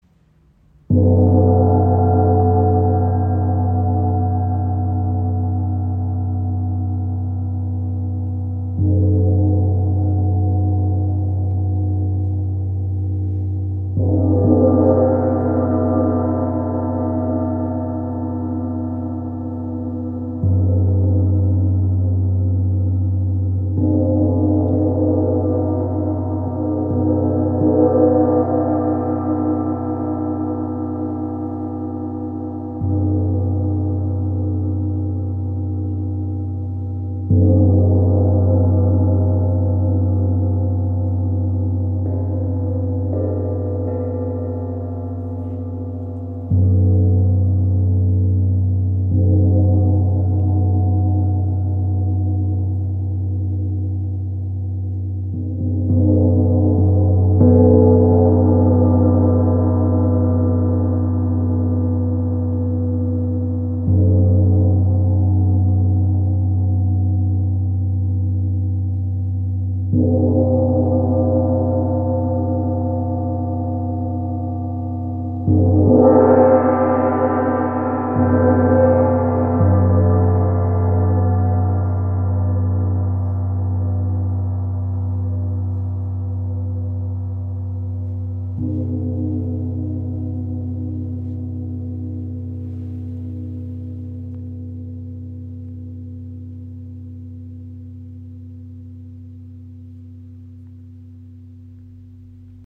• Icon Bronze und Nickel-Silber erzeugen warme, harmonische Klänge.
Die Instrumente zeichnen sich durch harmonische Obertöne, warme Resonanz und ausgewogene Klangtiefe aus.